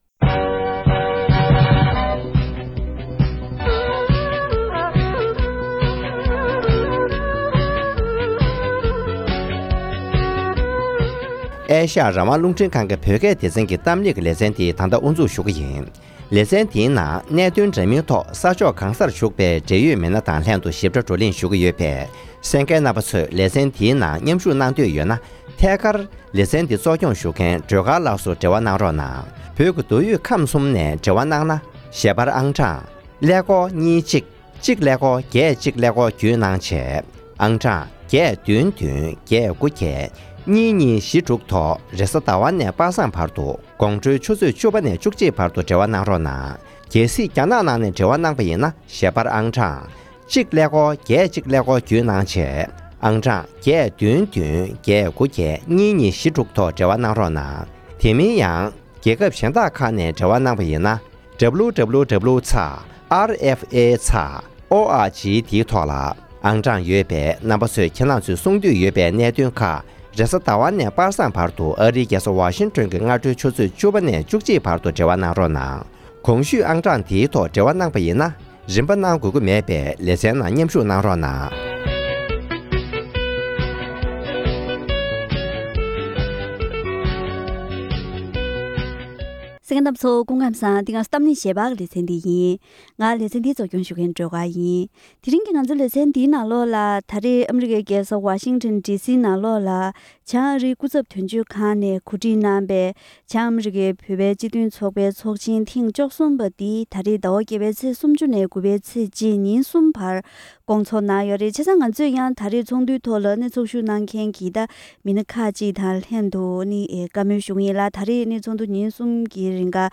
༄༅། །ཐེངས་འདིའི་གཏམ་གླེང་ཞལ་པར་ལེ་ཚན་འདིའི་ནང་། ཨ་རིའི་རྒྱལ་སར་སྐོང་ཚོགས་གནང་བའི་ བྱང་ཨ་རིའི་བོད་རིགས་སྤྱི་མཐུན་ཚོགས་པའི་ཚོགས་ཆེན་ཐེངས་༡༣ཐོག་གྲོས་ཆོས་དང་བཀའ་བསྡུར་ཇི་བྱུང་སྐོར་ལ་བྱང་ཨ་རིའི་དོན་གཅོད་དང་བྱང་ཨ་རིའི་སྤྱི་འཐུས་རྣམ་གཉིས་བཅས་འབྲེལ་ཡོད་ཚོགས་ཞུགས་མི་སྣ་༡༡ལྷག་དང་བཀའ་མོལ་ཞུས་པ་ཞིག་གསན་རོགས་གནང་།།